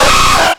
Cri de Nidoran♂ dans Pokémon X et Y.